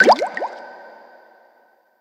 Bubble_Note.ogg